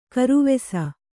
♪ karuvesa